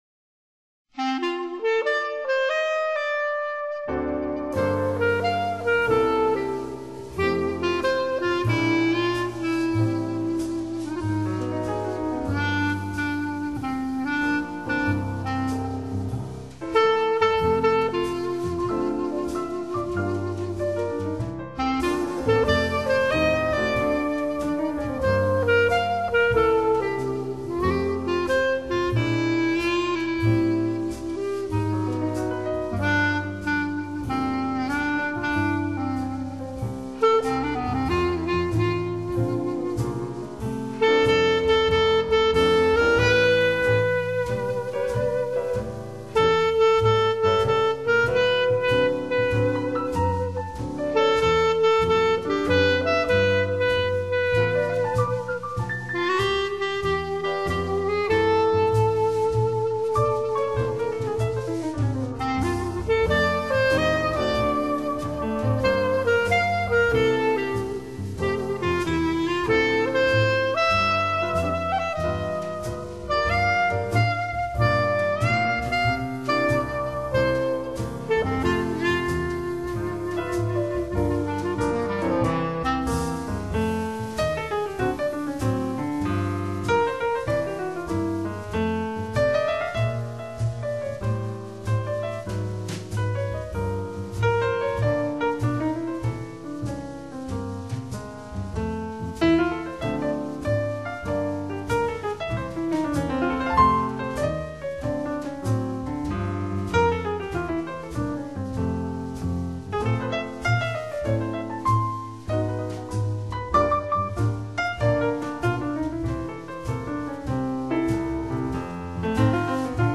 Jazz | 1CD | FLAC /分軌 | Size: 349 MB | Time: 64:37
clarinet & tenor sax
piano
bass
drums
Recorded: Tokyo, April-August 1986